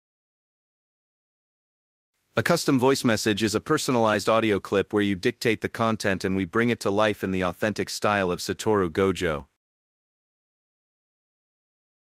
From his playful tone to his subtle sarcasm, every nuance of his voice is captured to perfection, making the experience feel like a direct interaction with the sorcerer himself.
2. Voice Magic: Using advanced voice synthesis technology and a team of experts who understand Gojo’s character, we create a high-quality audio file that sounds exactly like him.
We use state-of-the-art voice synthesis technology and voiceover artists who are experts in replicating Gojo’s tone, cadence, and personality.